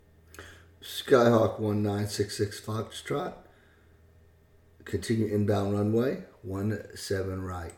Aviation Radio Calls
13b_TowerContinueInboundFinalRunwayOneSevenRight.mp3